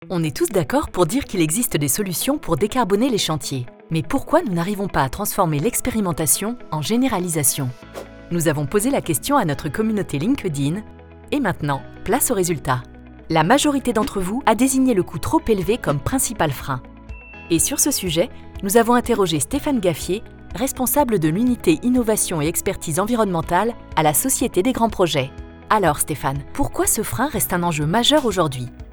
Institutionnel voix douce voix naturelle voix souriante Voix douce Catégories / Types de Voix Extrait : Votre navigateur ne gère pas l'élément video .